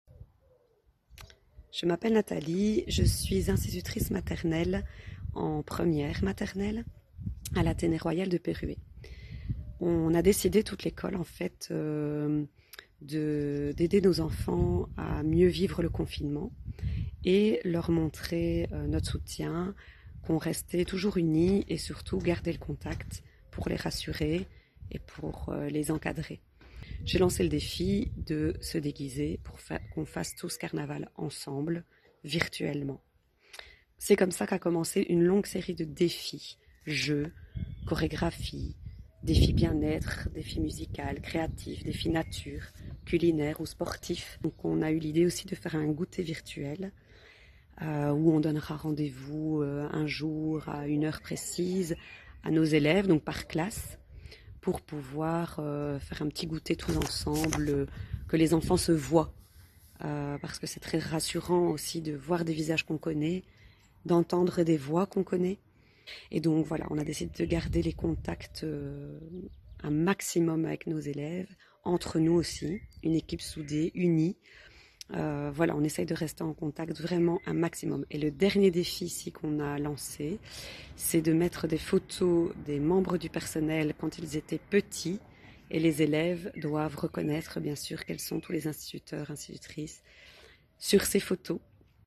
Des témoignages audio de professionnels de l’enseignement